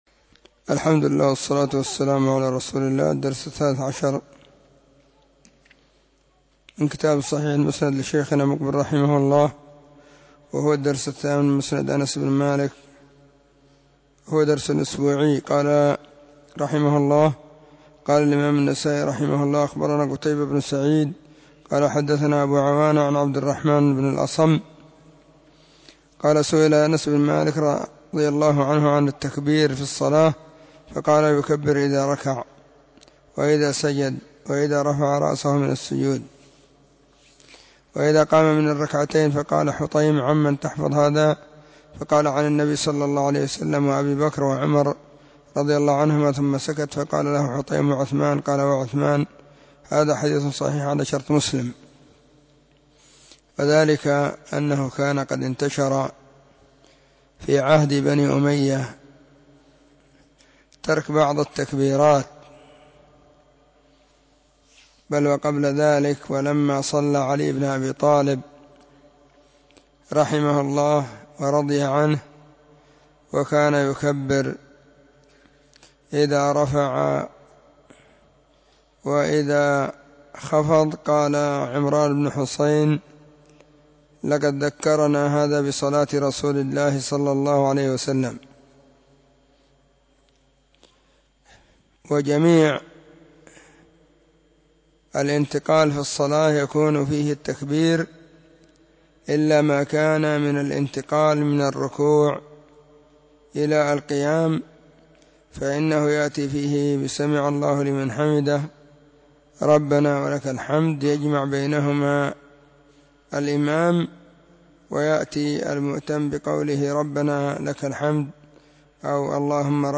📖 الصحيح المسند مما ليس في الصحيحين = الدرس: 13 🎙 الدرس :-8
خميس -} 📢مسجد الصحابة – بالغيضة – المهرة، اليمن حرسها الله.